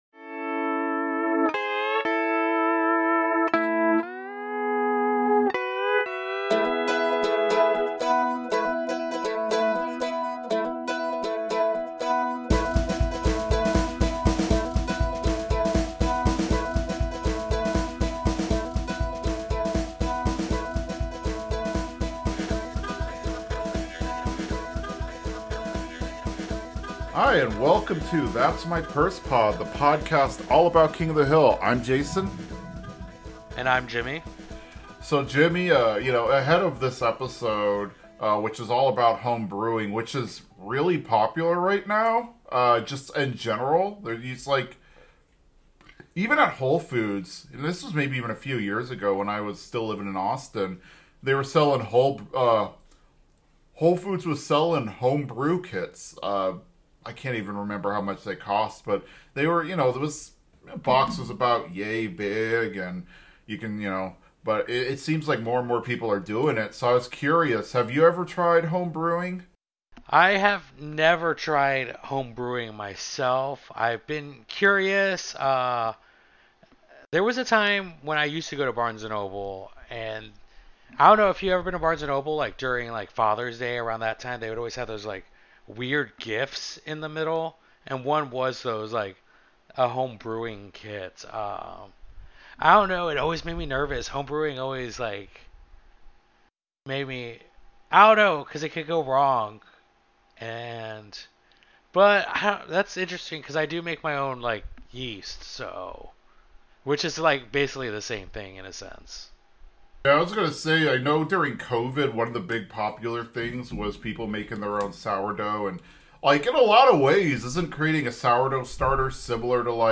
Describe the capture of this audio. Listen in to find out!(apologies for a few pops, the microphone was a bit lose and hit the wall a few times)